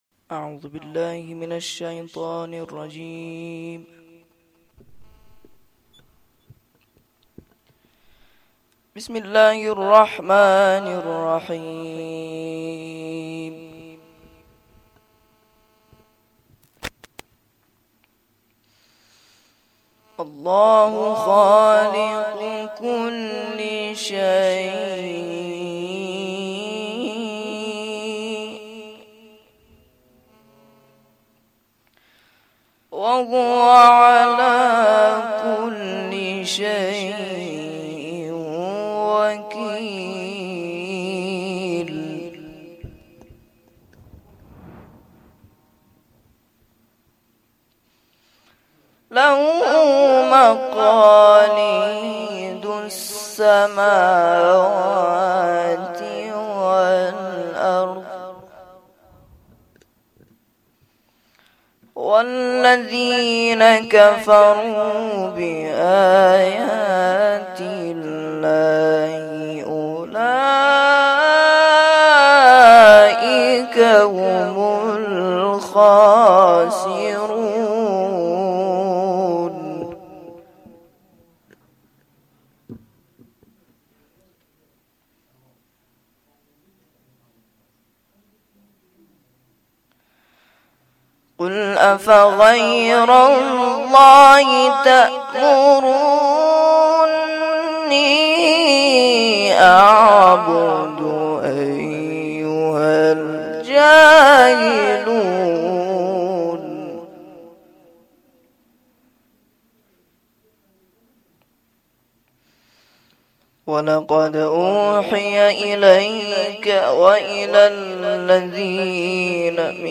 گزارش صوتی جلسه رحلت امام
روضه